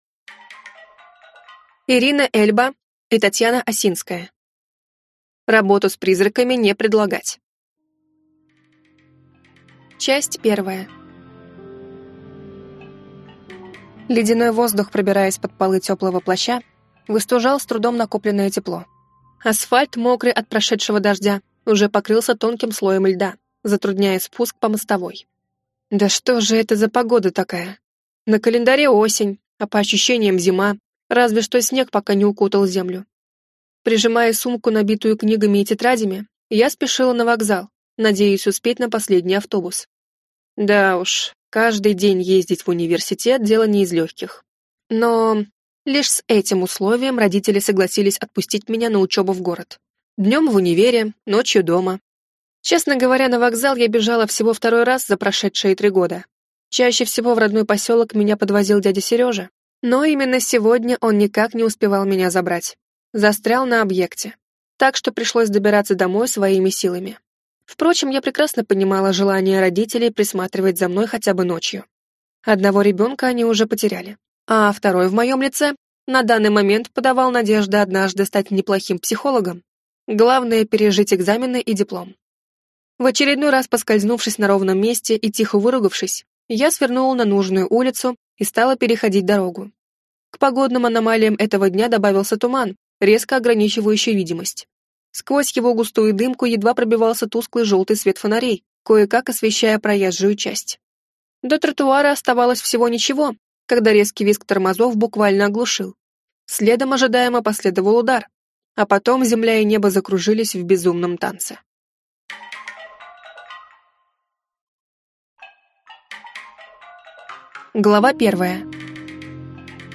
Аудиокнига Работу с призраками не предлагать | Библиотека аудиокниг